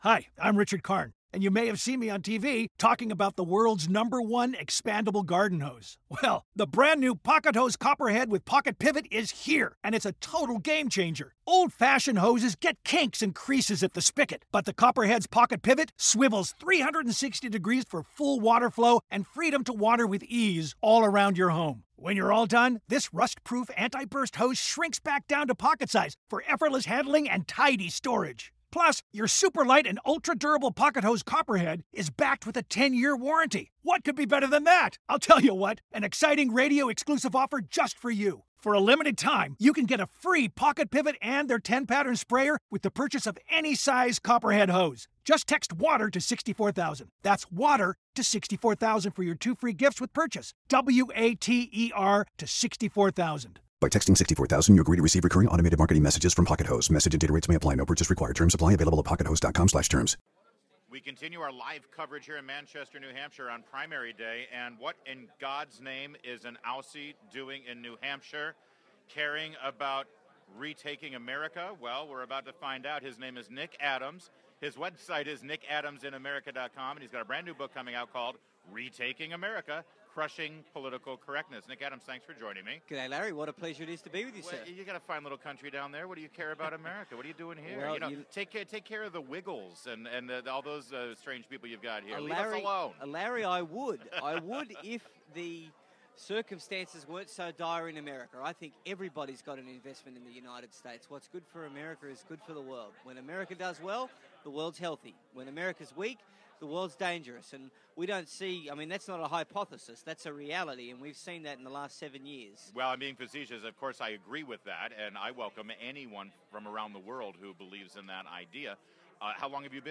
WMAL's Larry O'Connor Interviews Nick Adams